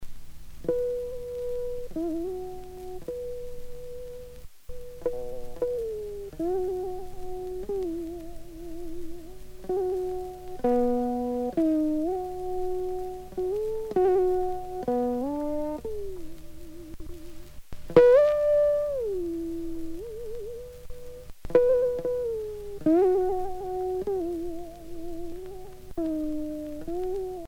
enfantine : berceuse
Pièce musicale éditée